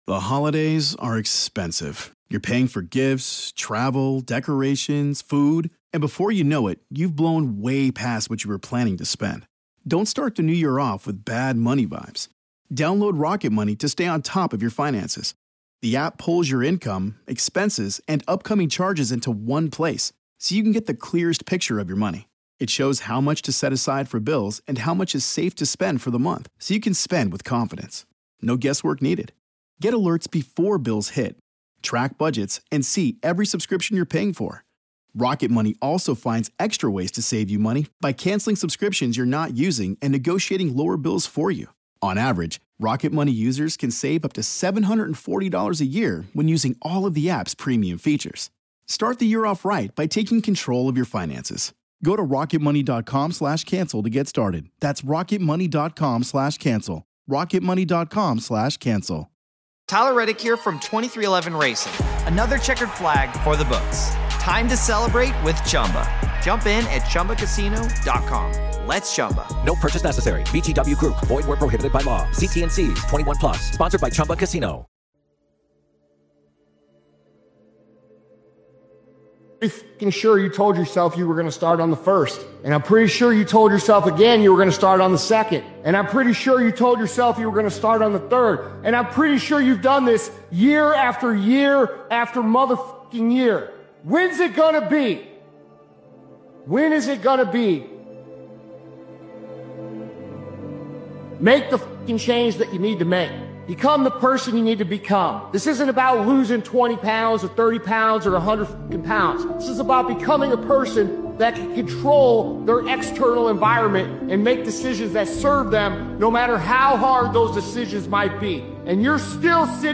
Powerful New Year Motivational Speech Video is a focused and forward-driven motivational video created and edited by Daily Motivations.